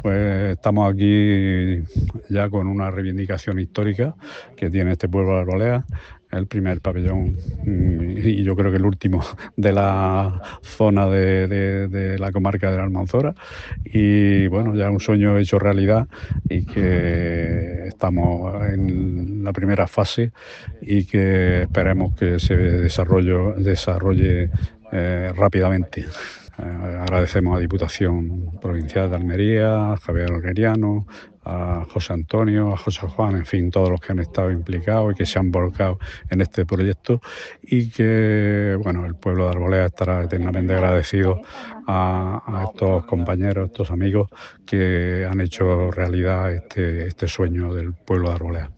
Jose-Juan-Ramos-Alcalde-de-Arboleas.mp3